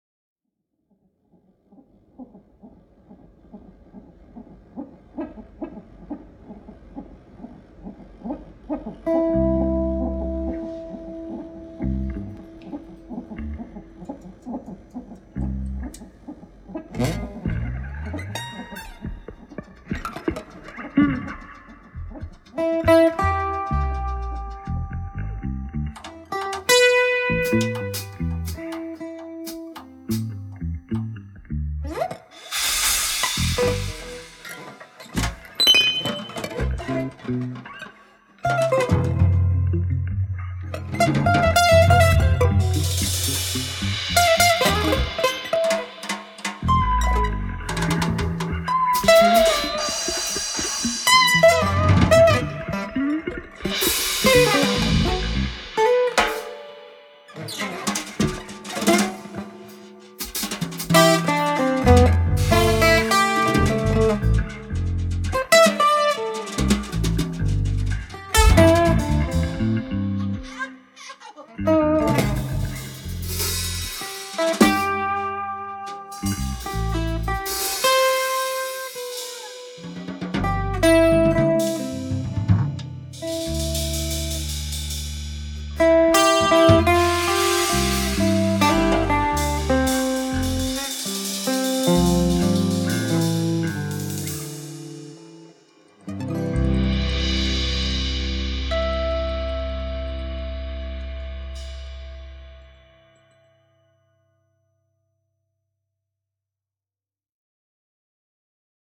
Genere: Fusion.